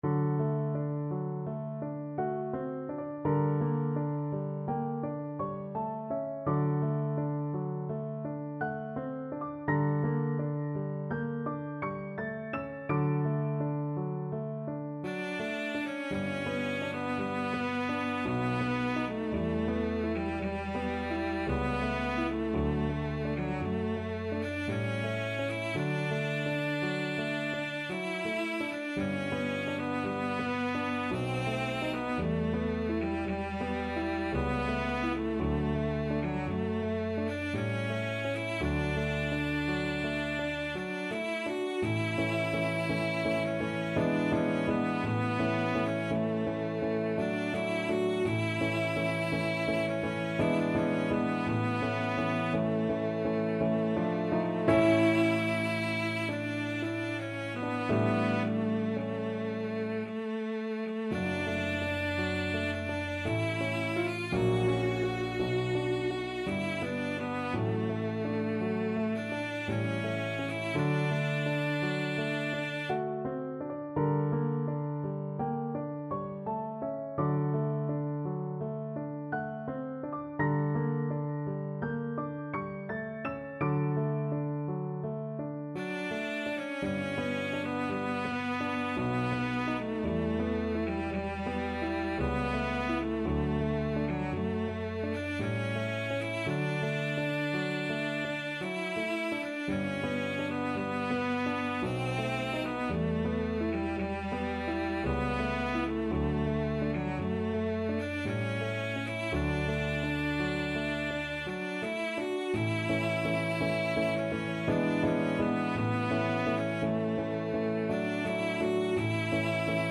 Cello Classical Cello Classical Cello Free Sheet Music Lob der Tranen, D.711
3/4 (View more 3/4 Music)
D major (Sounding Pitch) (View more D major Music for Cello )
~ = 56 Ziemlich langsam
Classical (View more Classical Cello Music)